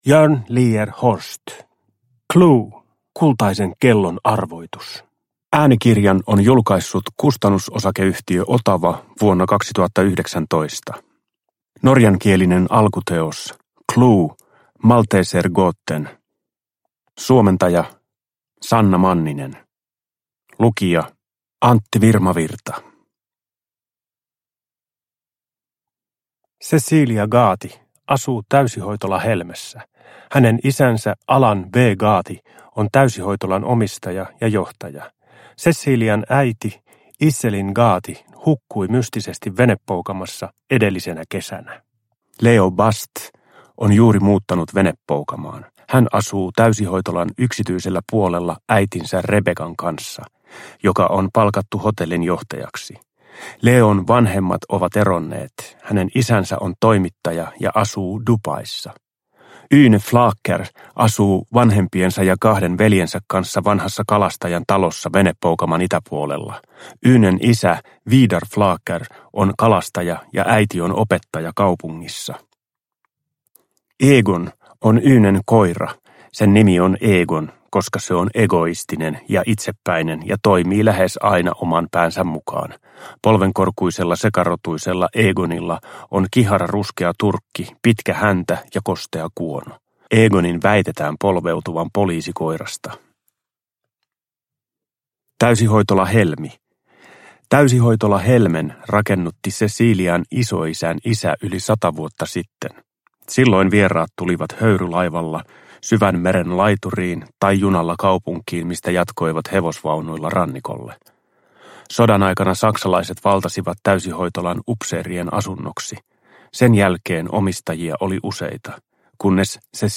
CLUE - Kultaisen kellon arvoitus – Ljudbok – Laddas ner